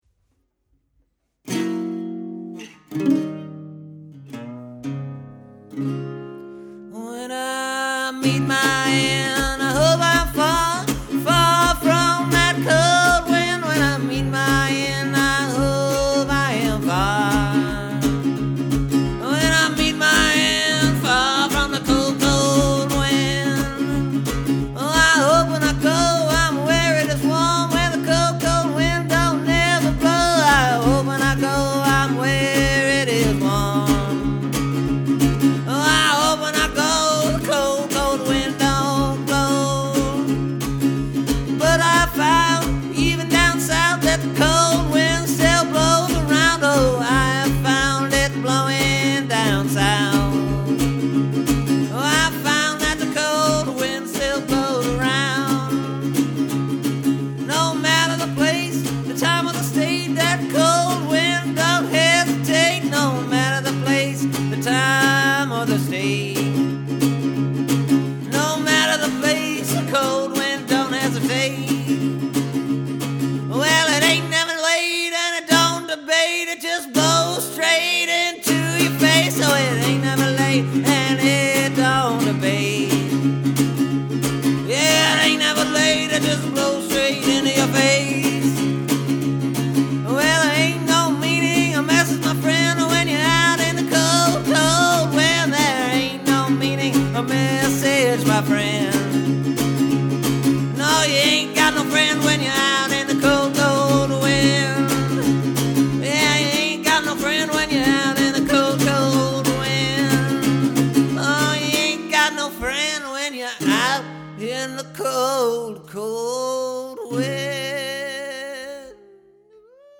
This new version is a bit hotter.
This new one moves.